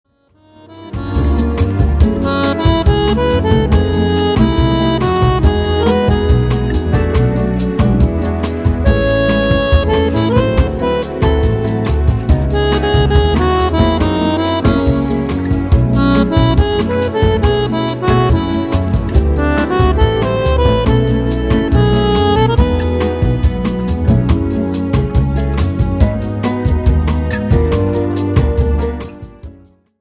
JAZZ  (3.41)